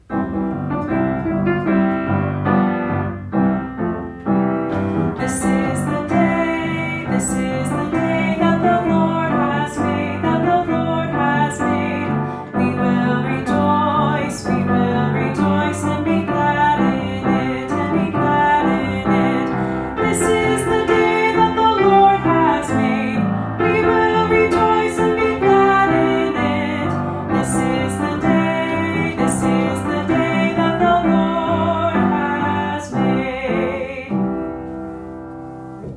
Scripture Songs